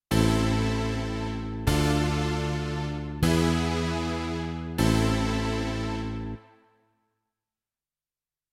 ♪イ短調の和音進行(mp3)